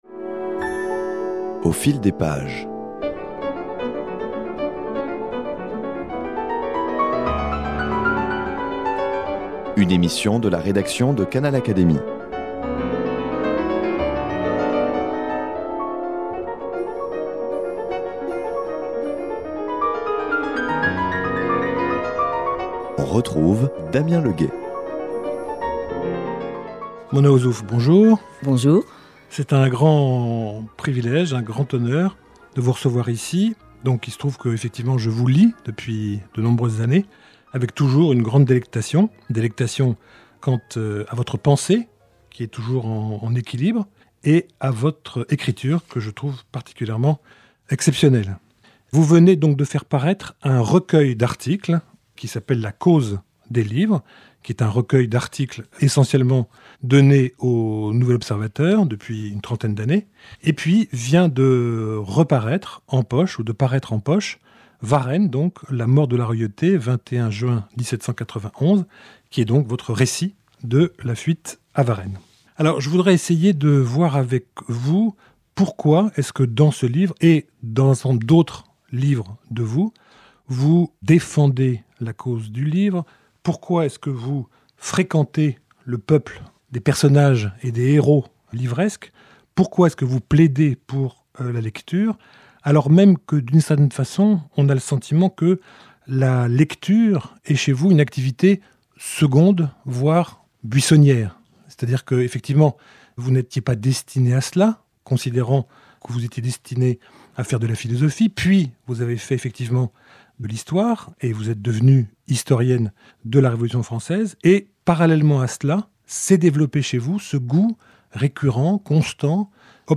A l’occasion de la publication de son livre La cause des livres (Gallimard), recueil d’articles parus dans l’hebdomadaire Le Nouvel Observateur depuis trente ans, Mona Ozouf, grande dame des lettres françaises, membre du jury Fémina, nous entretient de son intérêt jamais démenti pour la littérature.